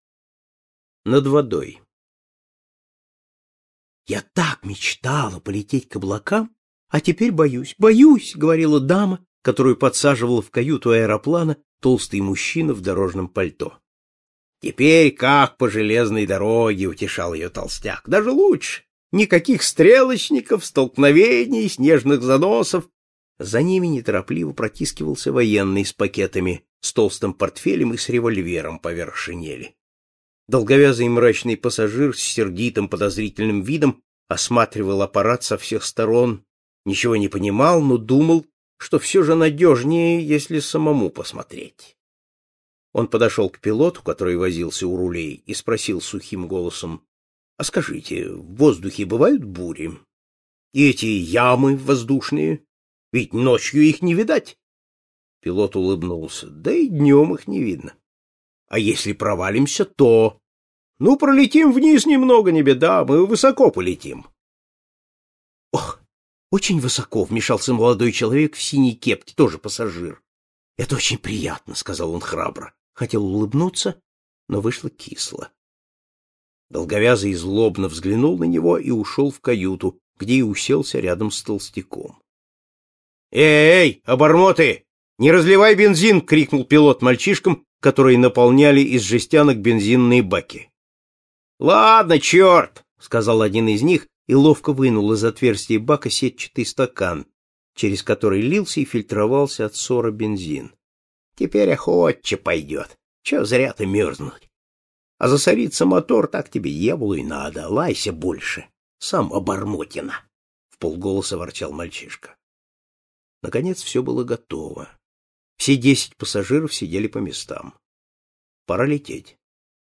Над водой - аудио рассказ Житкова - слушать онлайн